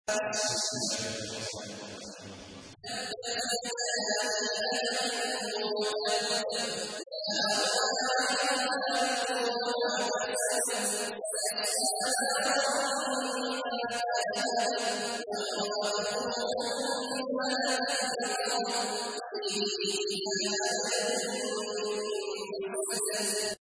تحميل : 111. سورة المسد / القارئ عبد الله عواد الجهني / القرآن الكريم / موقع يا حسين